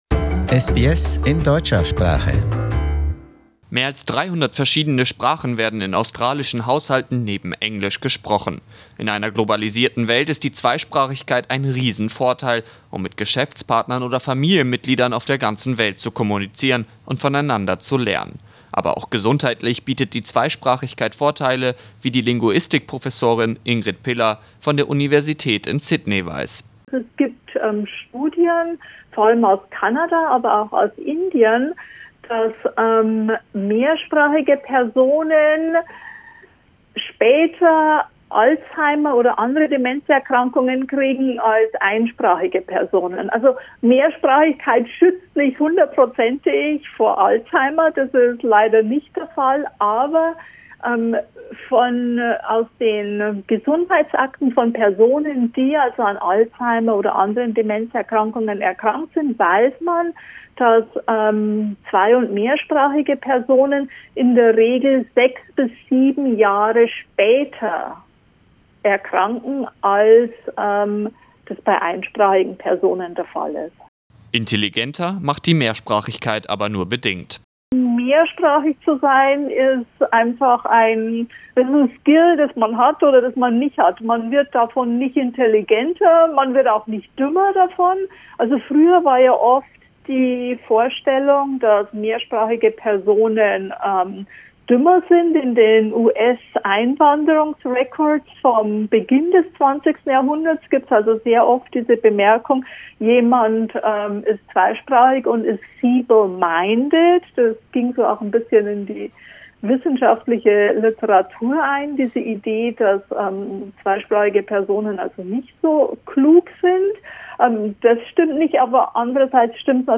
People who speak two or more languages get Alzheimer´s later compared to people who just speak one - but they are not more intelligent. Learn more about this in our interview